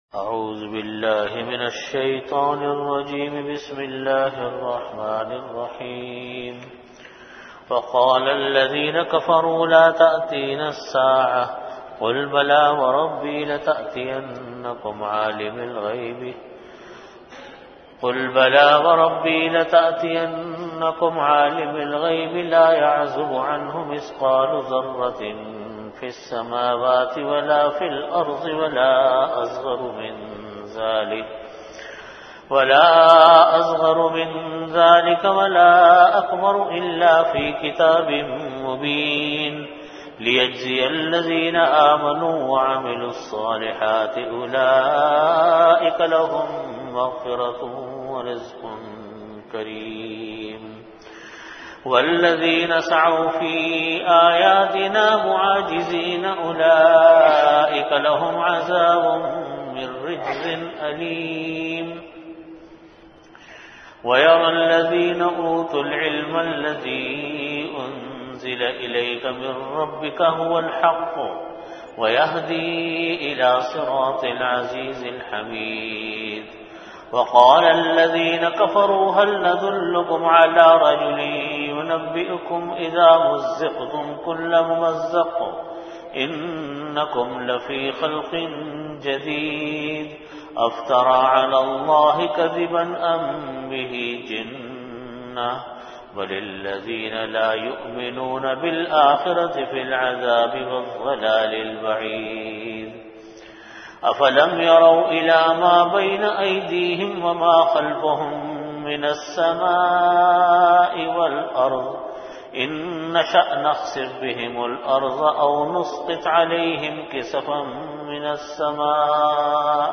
Time: After Asar Prayer Venue: Jamia Masjid Bait-ul-Mukkaram, Karachi